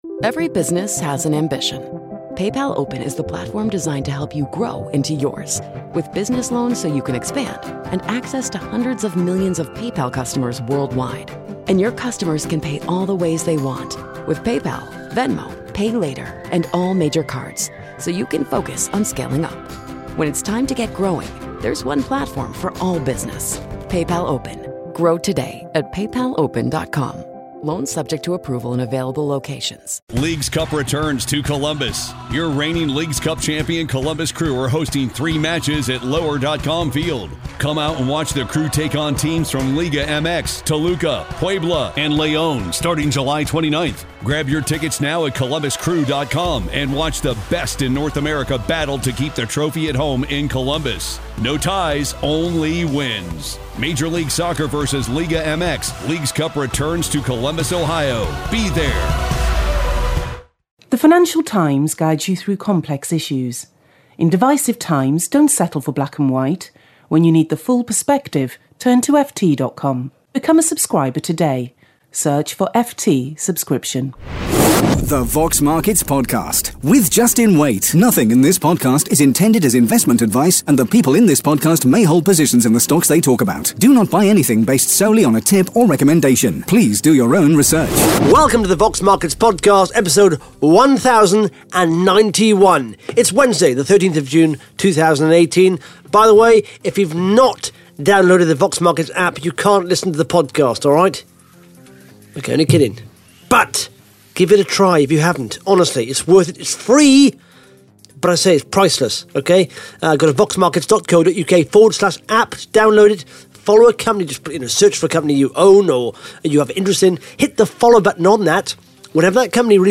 (Interview starts at 2 minutes 14 seconds)